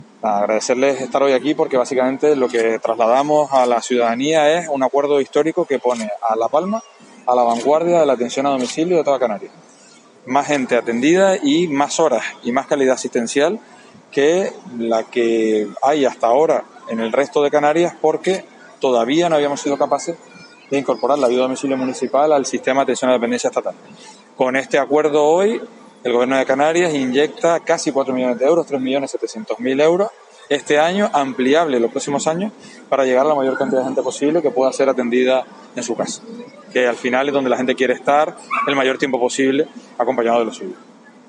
Director General de Dependencia y Discapacidad, Miguel Montero.mp3